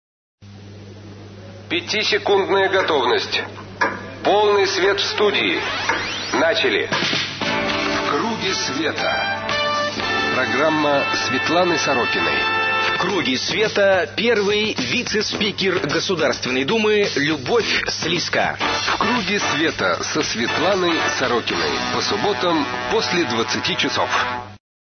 4 марта 2006 г. Гостья - Л.К.Слиска
Аудио: анонс -